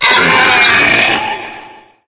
main Divergent / mods / Soundscape Overhaul / gamedata / sounds / monsters / psysucker / die_0.ogg 20 KiB (Stored with Git LFS) Raw Permalink History